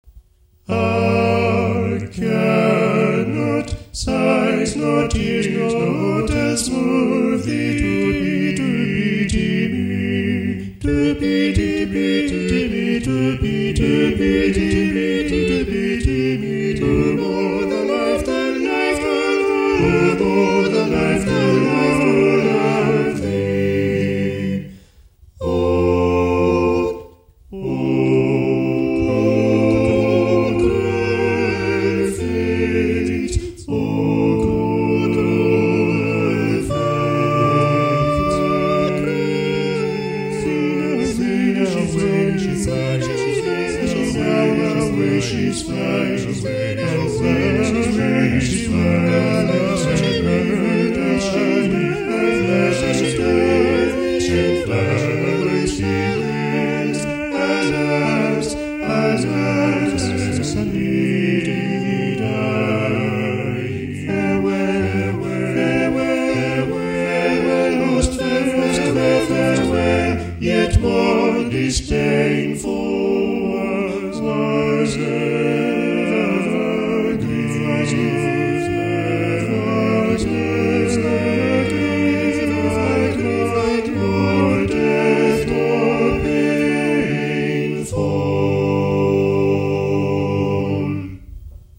Madrigals for Six Voices